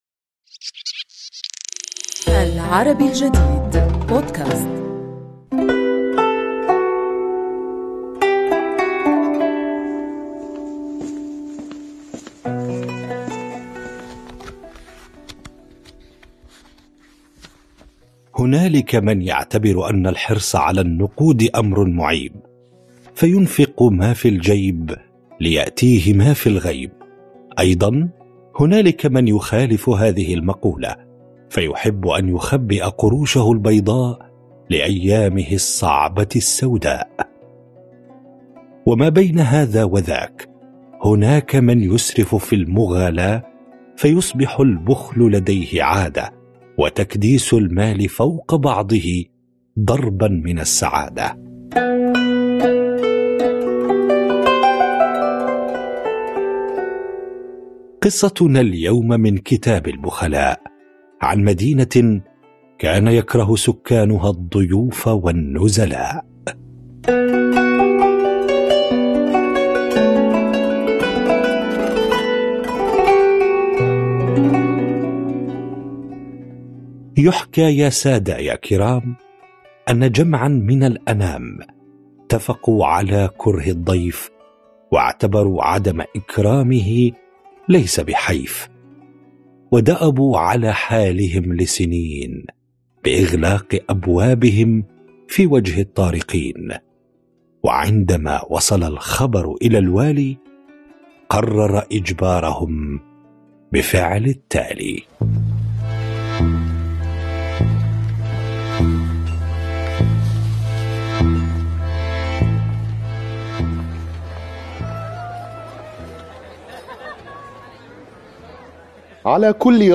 الحكواتي